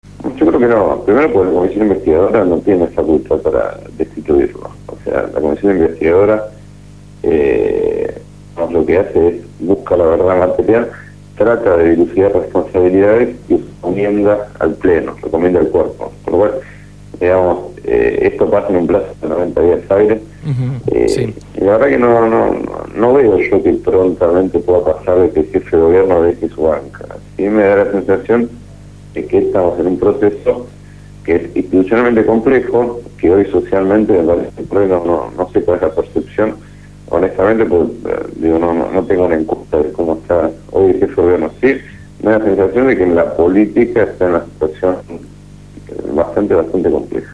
Diego Kravetz, legislador por el FPV, fue entrevistado